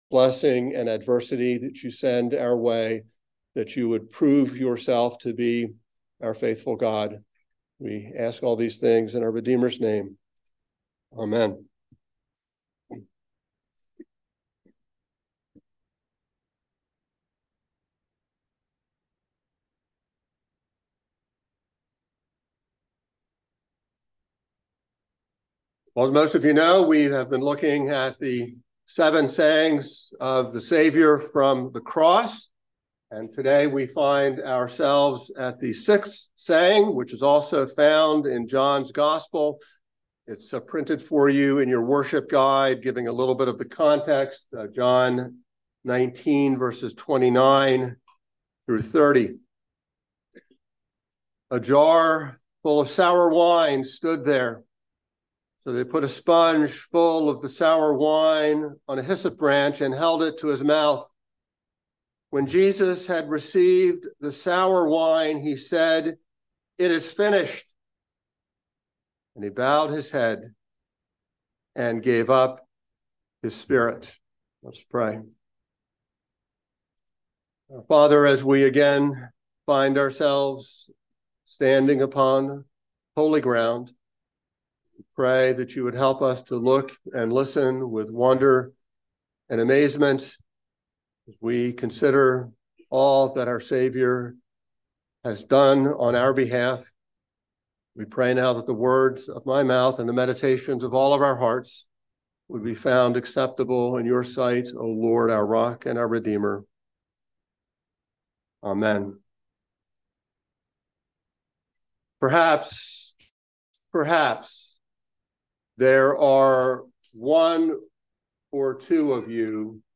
by Trinity Presbyterian Church | Jan 5, 2024 | Sermon